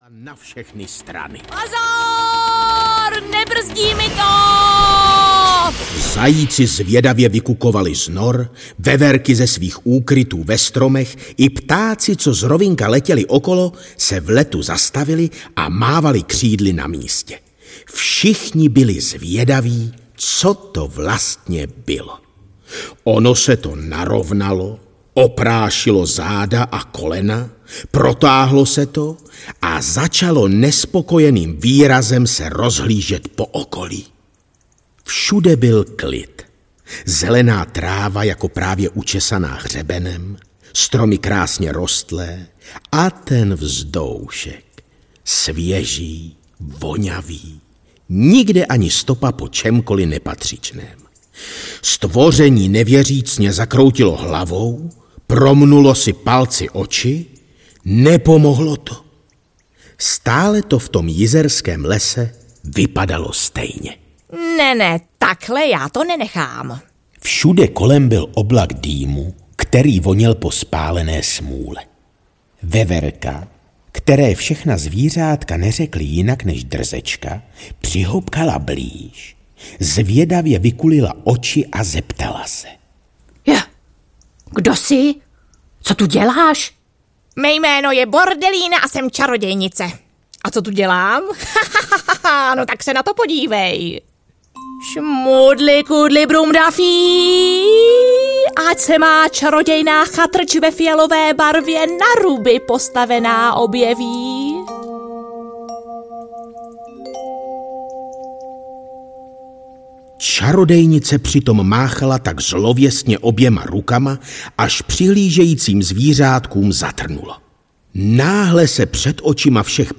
Čarodějnice Bordelína audiokniha
Ukázka z knihy